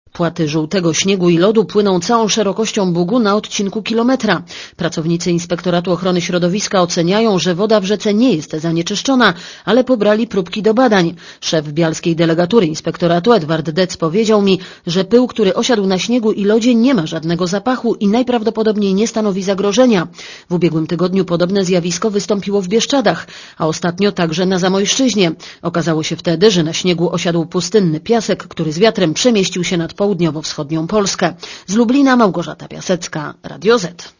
Źródło: Archiwum Relacja reportera Radia Zet Służby sanitarne pobrały już próbki zanieczyszczeń.